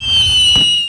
flying.wav